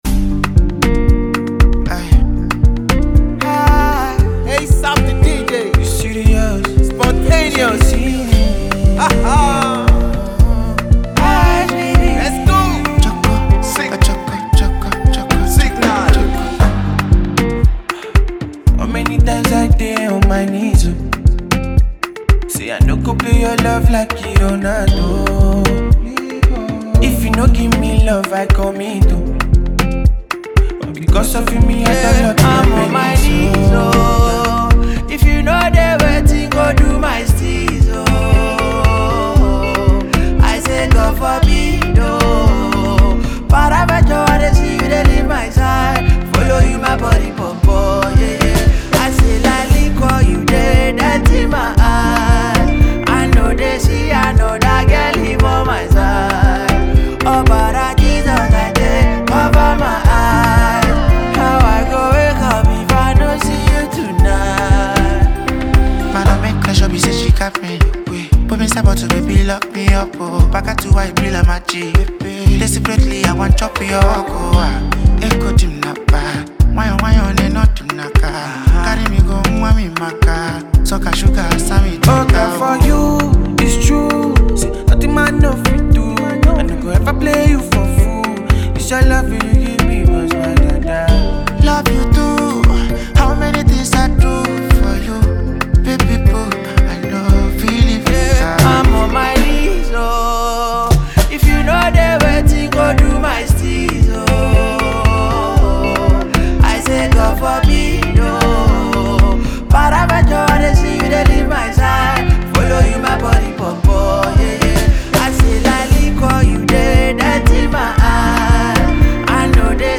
With its infectious beat and catchy hooks
is poised to become a party anthem.